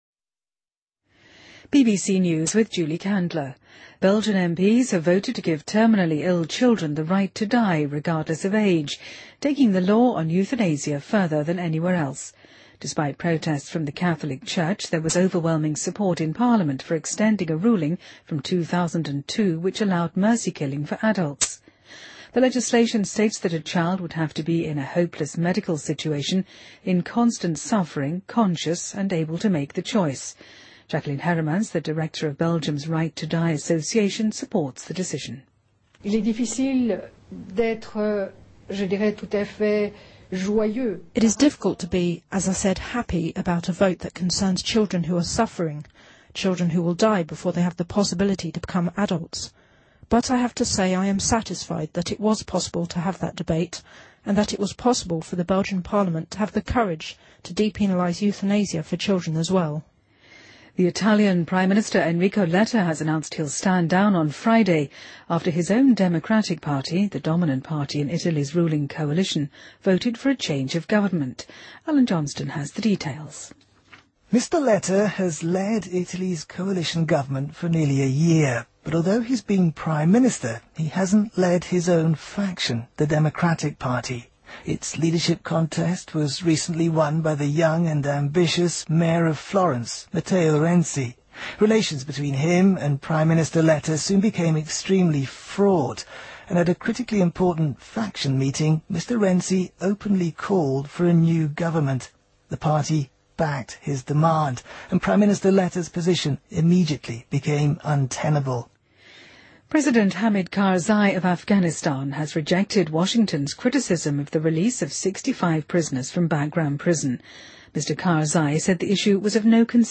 BBC news,2014-02-14